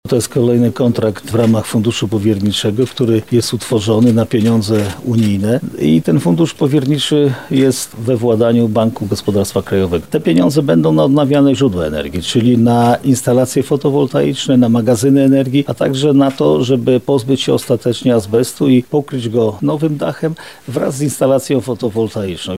Jarosław Stawiarski-mówi marszałek województwa lubelskiego Jarosław Stawiarski.